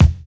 NY 12 BD.wav